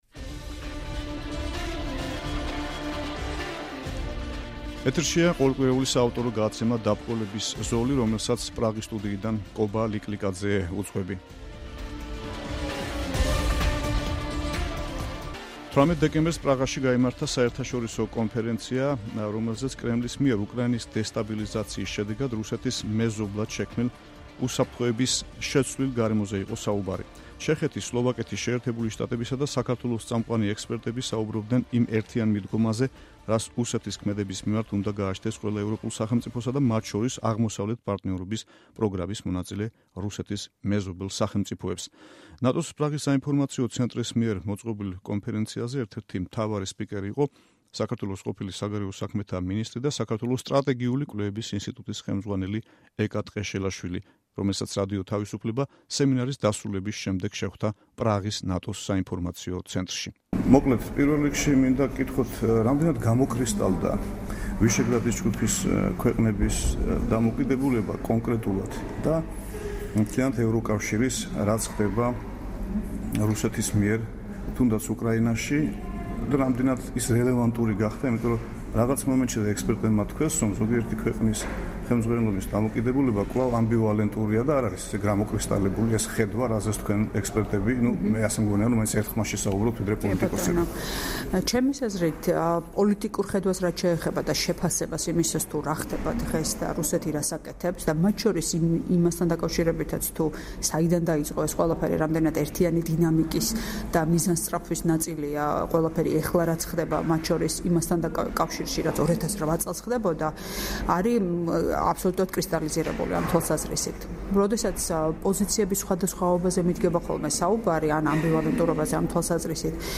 საუბარი ეკა ტყეშელაშვილთან